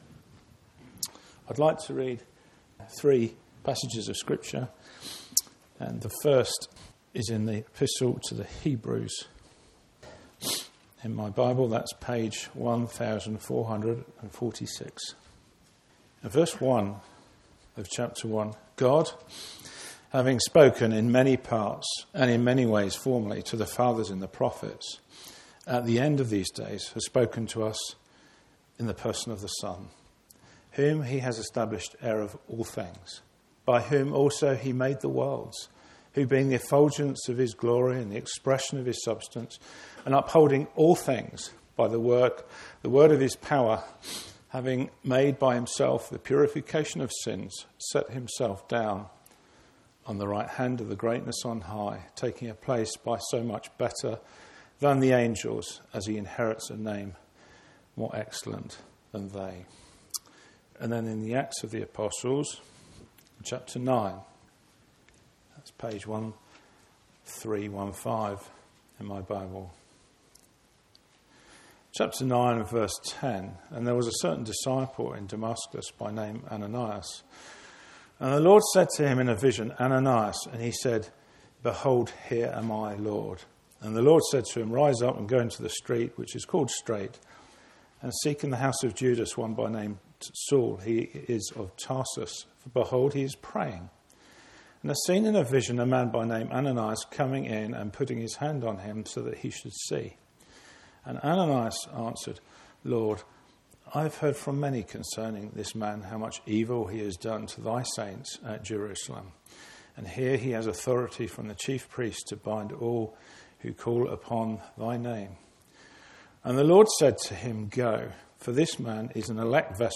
Bible Teaching (Addresses)